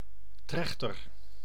Ääntäminen
US : IPA : [ˈfə.nᵊl] UK : IPA : /ˈfʌn.əl/